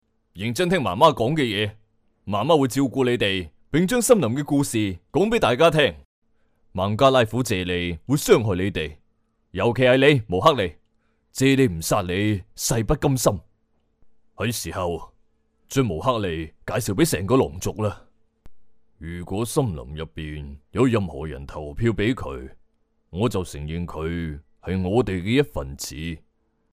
男声 Male Voice-公司名
男粤13 港式粤语广式粤语 人物角色-大叔